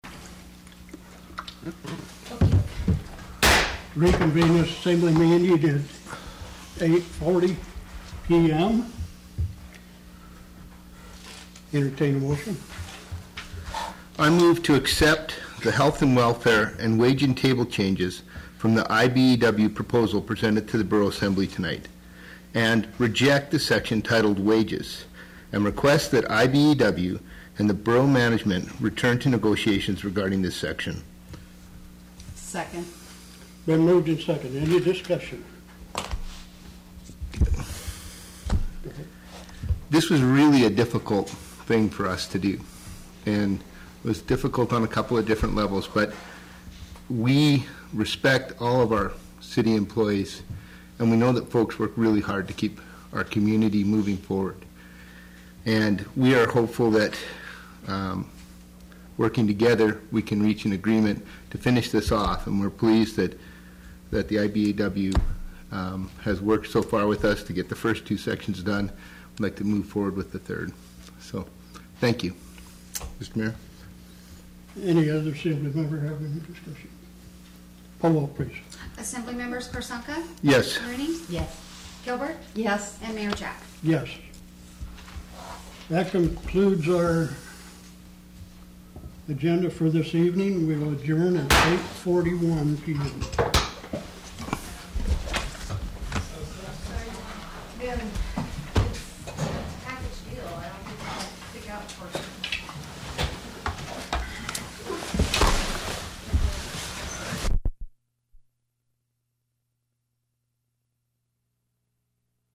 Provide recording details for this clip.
The Wrangell Borough Assembly held a special meeting on June 8th, 2017 regarding the local IBEW worker strike.